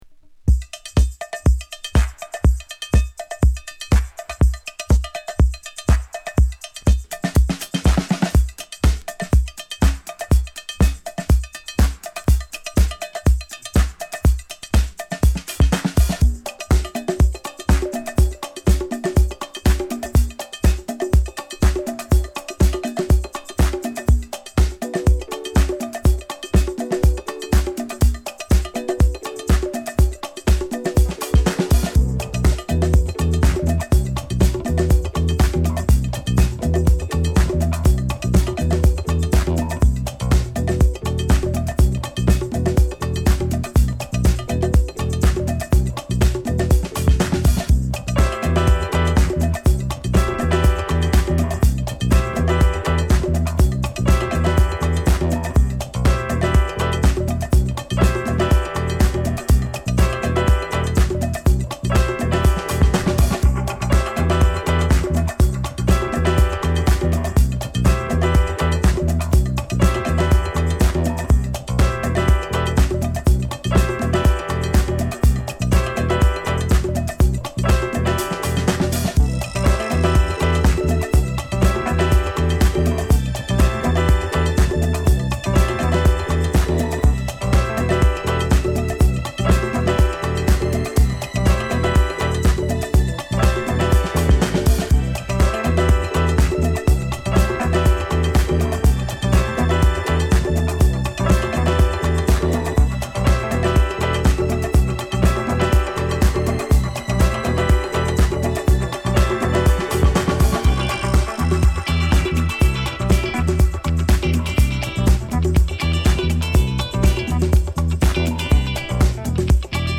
4トラックとも暖かいディスコ〜ハウスのハイブリッドトラックで◎！！
Deep House